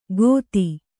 ♪ gōti